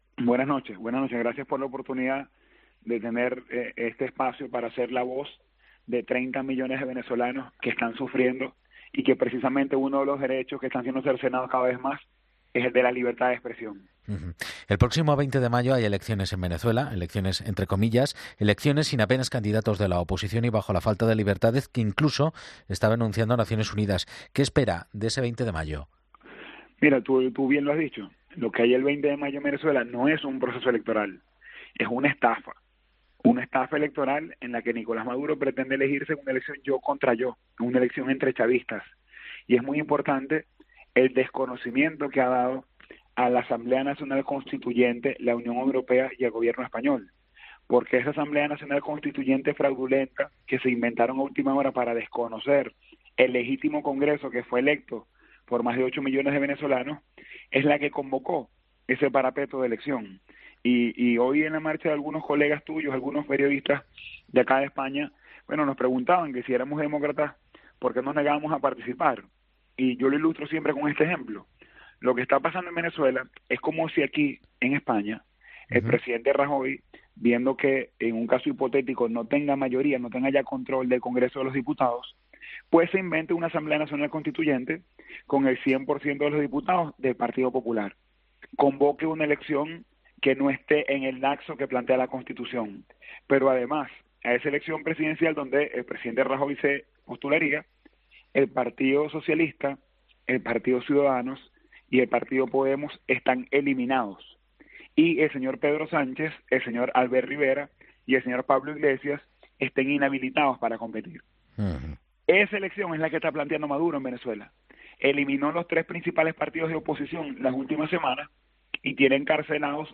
Uno de los organizadores de la manifestación venezolana de este sábado en Madrid, Lester Toledo, ha asegurado en una entrevista en la cadena COPE que el proceso electoral del próximo 20 de mayo en Venezuela es una “estafa electoral” en la que el presidente, Nicolás Maduro, pretende “elegirse en un yo contra yo”, asegura.